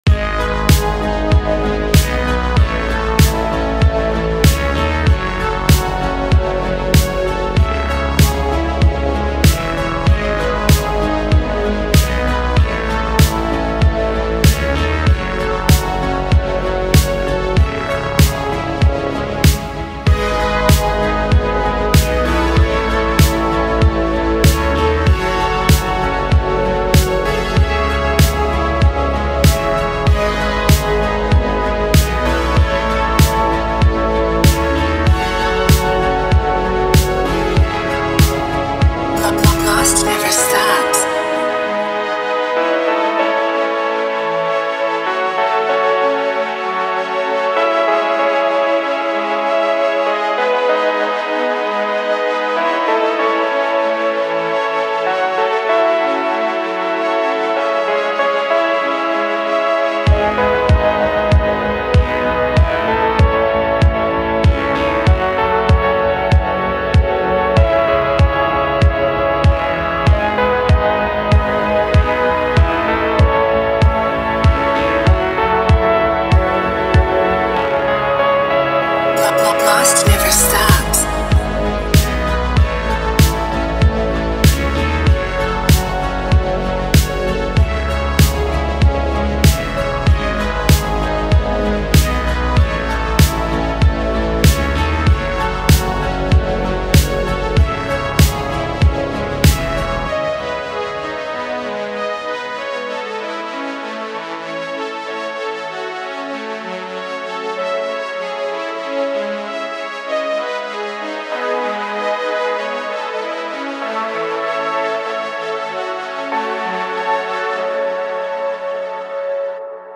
Zg0n4st2UUcvBWmk_kavinsky_type_beat.mp3